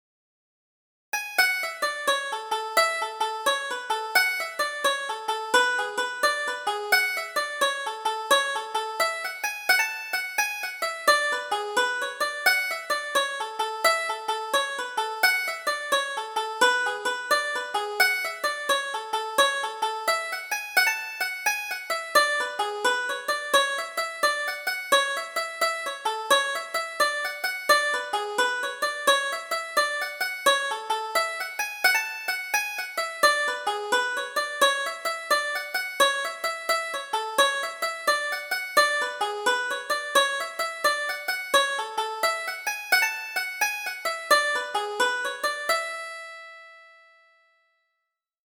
Double Jig: Saddle the Pony - 2nd Setting